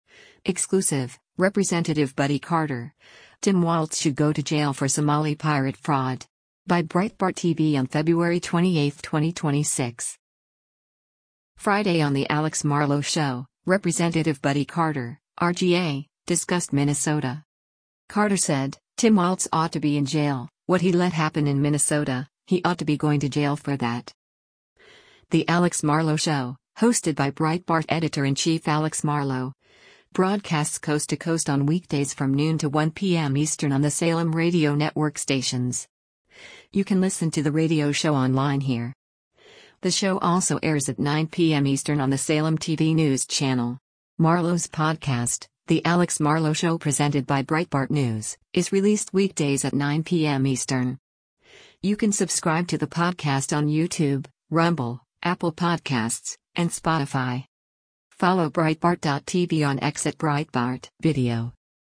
Friday on “The Alex Marlow Show,” Rep. Buddy Carter (R-GA) discussed Minnesota.